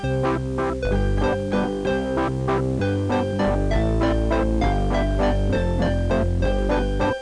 00015_Sound_Atmo.mp3